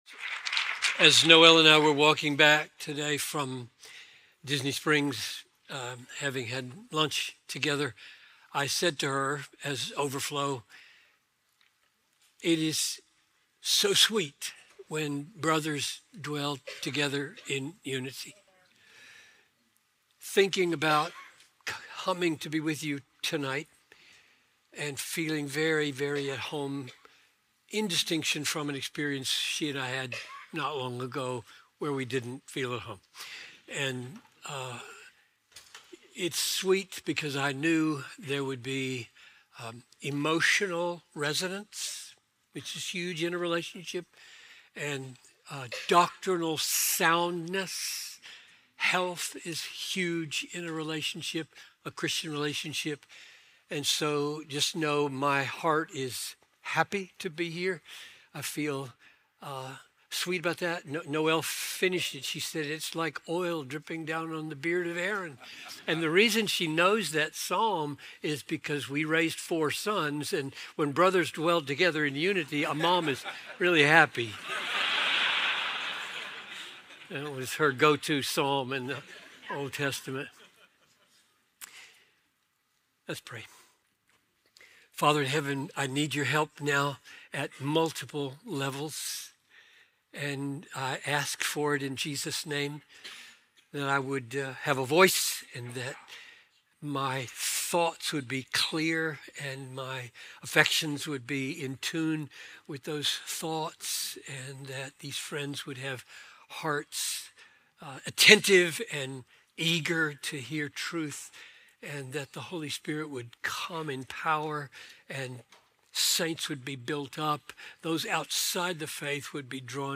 Speaker: John Piper
2025 Pastors Conference: Christ Our Glory